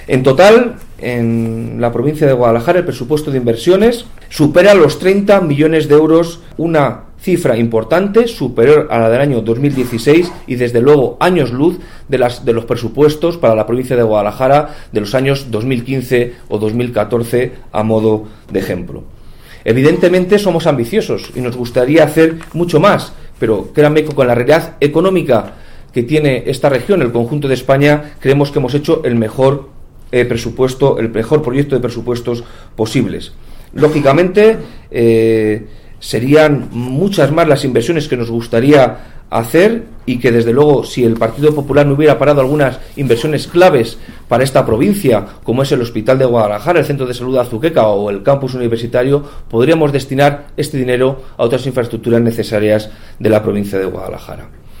El delegado de la Junta en Guadalajara sobre el presupuesto de 2017 en la provincia